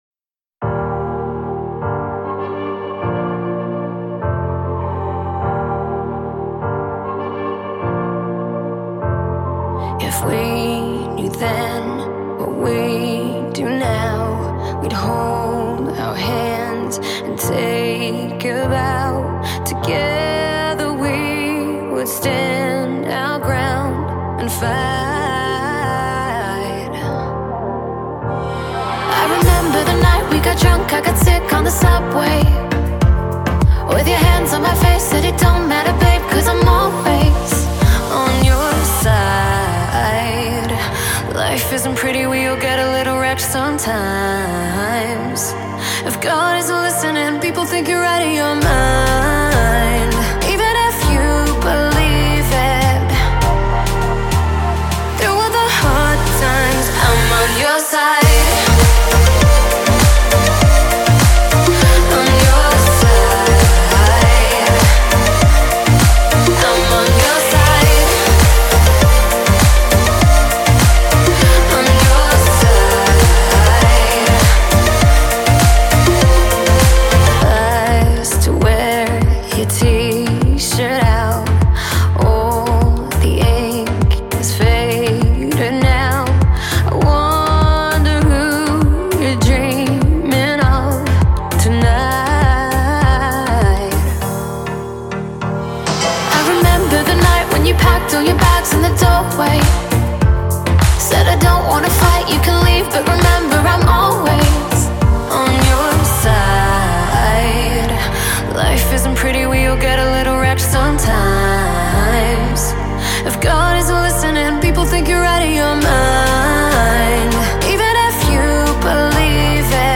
Хорошая попса, мне нравится